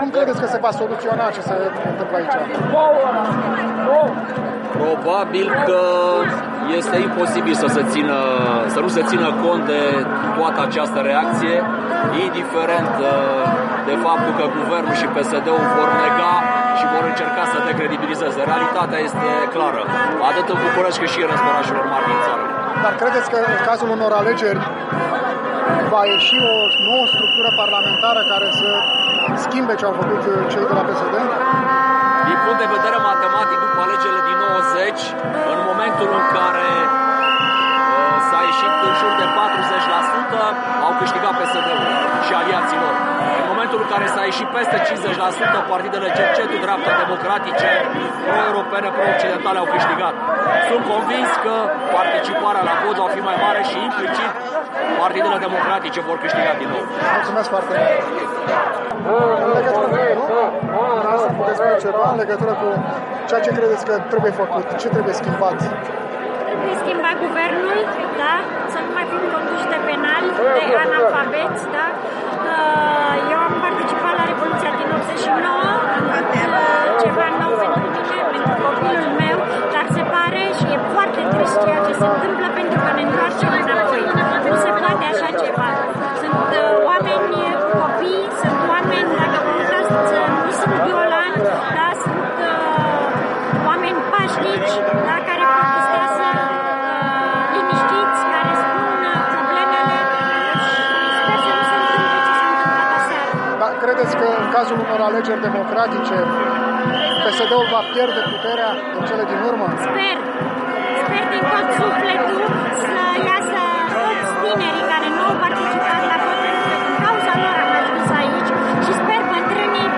S-a scandat mult, s-au fluierat, dar s-a și stat pe jos cu spatele la guvern, în semn de dispreț indiferent și rezistență pasivă și nonviolentă.
Am vorbit cu câțiva dintre cei prezenți la miting pentru a afla cum cred că se poate soluționa impasul.